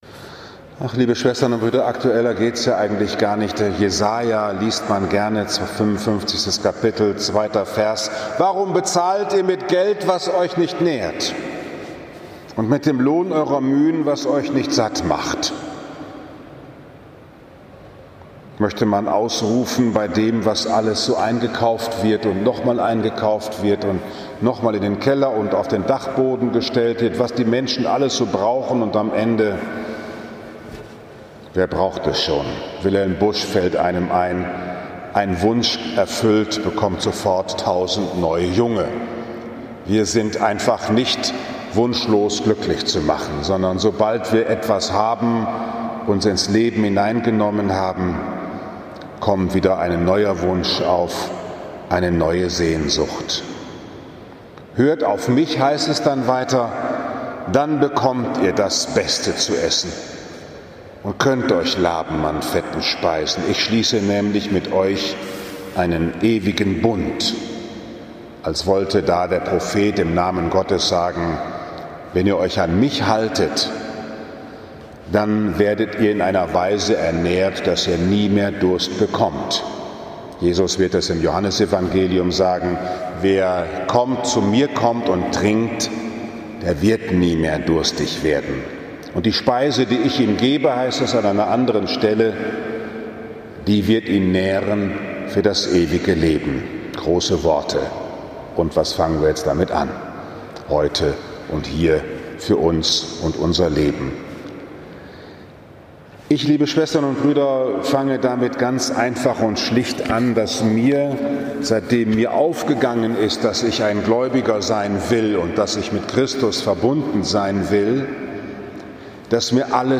1. August 2020, 17 Uhr Liebfrauenkirche Frankfurt am Main, 18. So. i. J. A
Bruder Paulus´ Kapuzinerpredigt